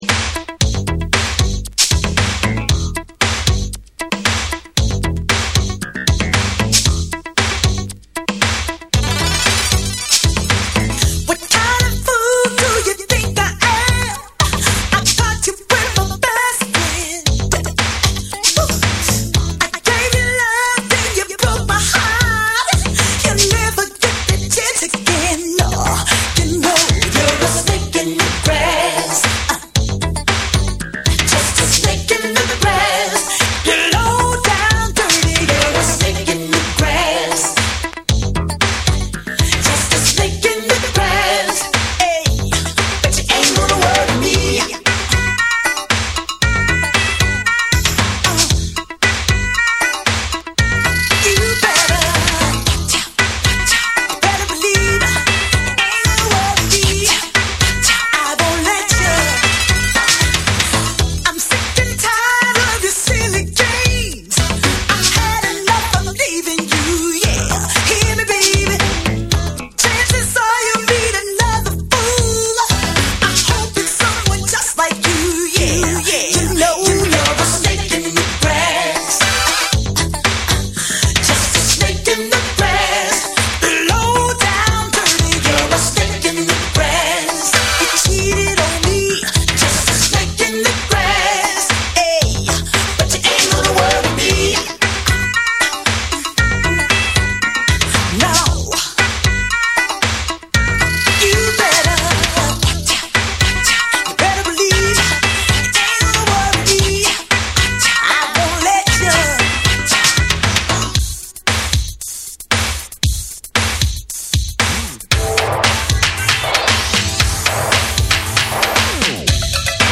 USのファンクバンド
ダークでスリリングなベースラインが効いた
洗練された80sエレクトロ・ファンクの魅力が詰まった一枚！
DANCE CLASSICS / DISCO